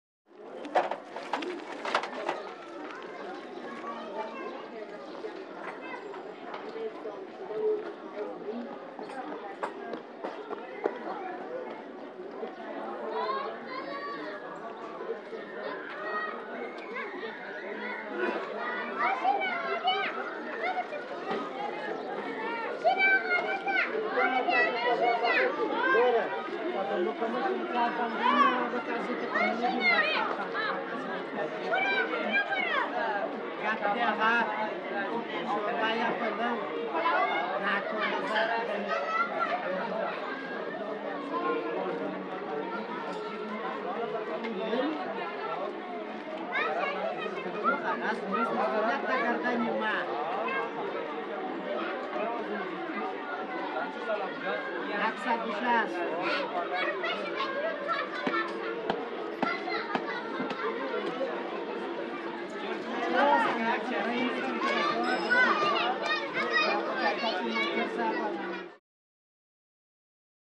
Market; Afghan; Afghan Market Atmosphere. Medium Sized Mobile Crowd With Light Sparrows.